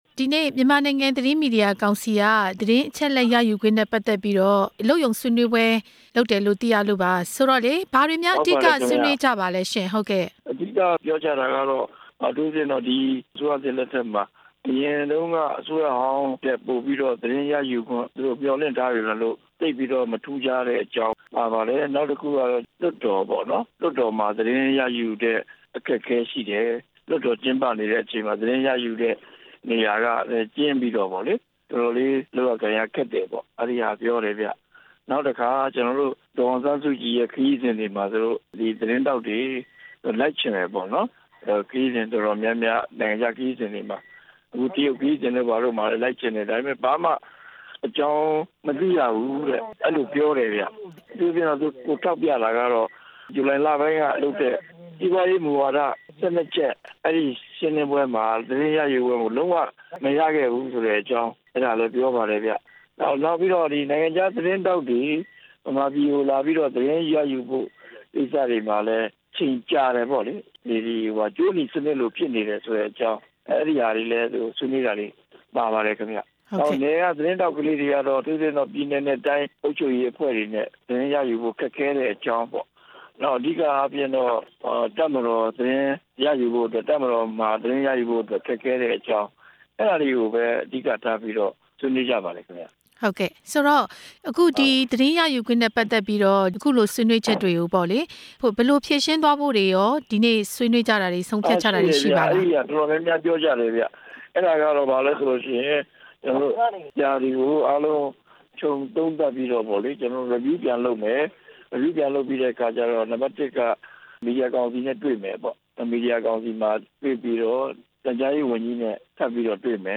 သတင်းရယူခွင့်မှာ စိန်ခေါ်မှု၊ စာရေးဆရာ မုံရွာအောင်ရှင် နဲ့ မေးမြန်းချက်